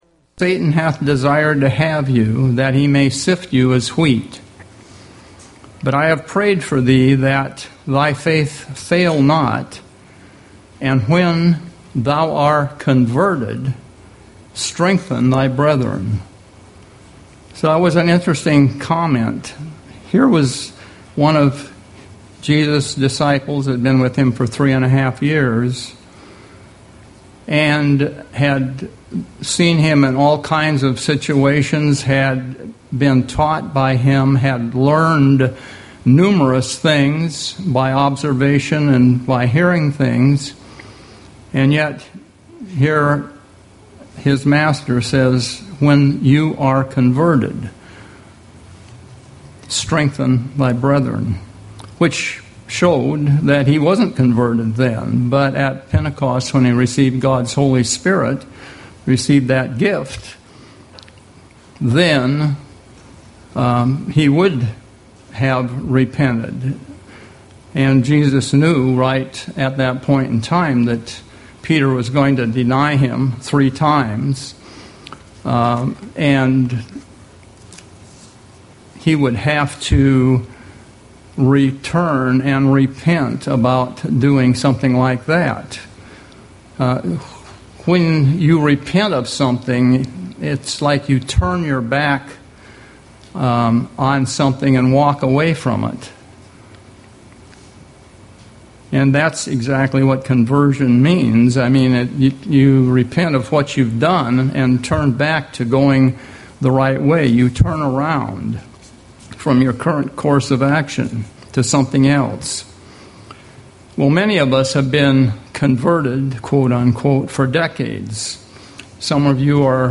Given in Seattle, WA
UCG Sermon Studying the bible?